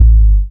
808s
Boomkic.wav